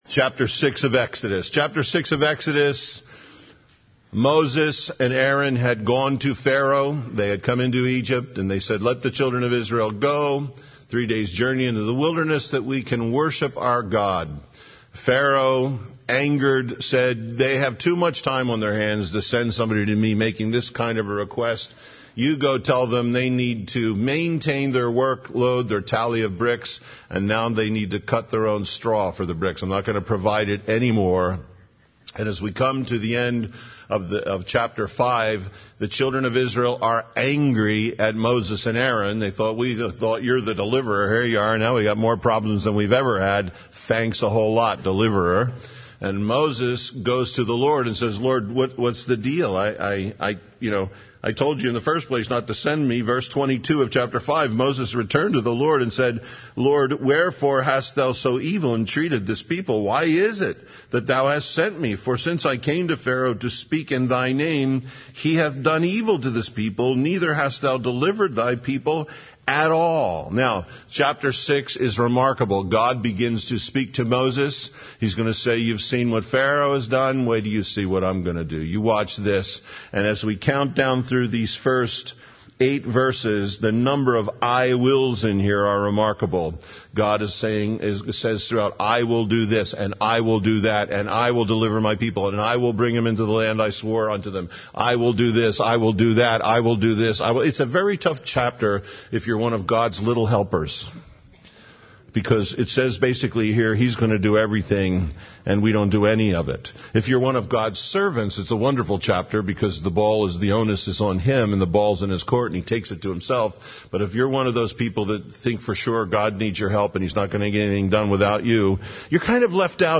Original Teaching